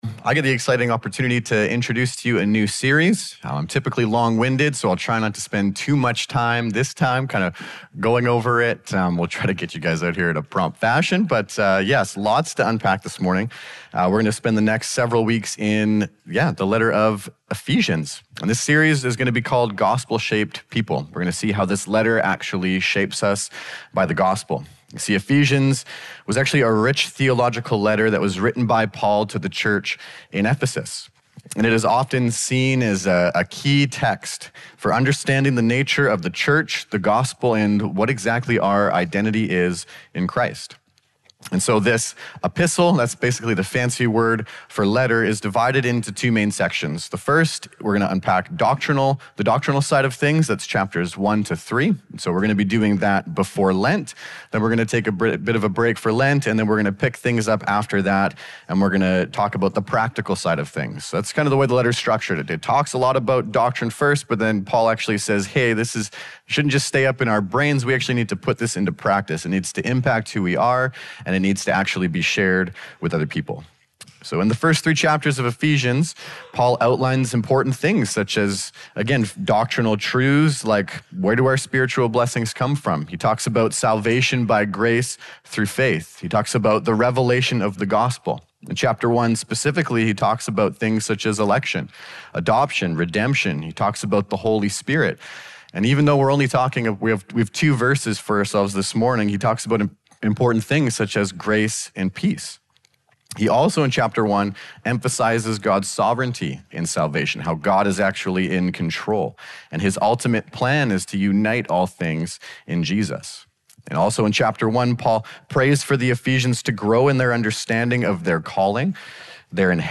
Épisode de l’émission · Central Community Church | Sermon Audio · 05/01/2025 · 1 s